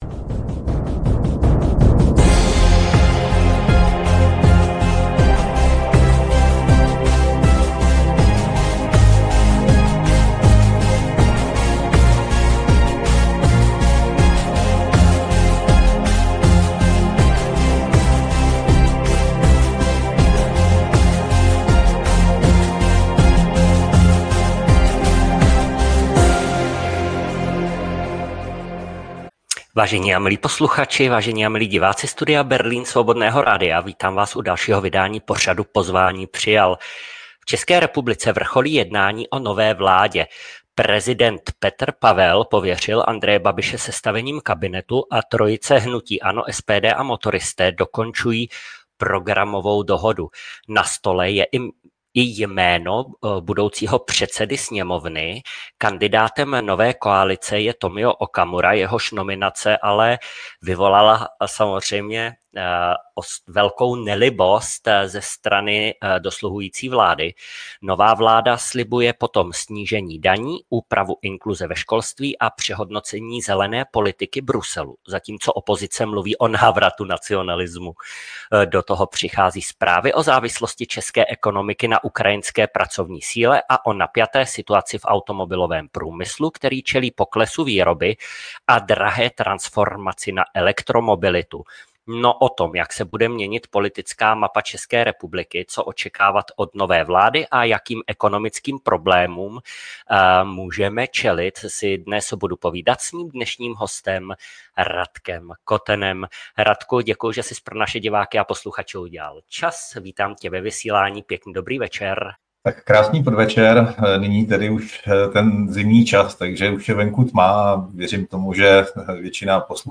O tom všem hovoří ve Studiu Berlín můj host — Radek Koten (SPD).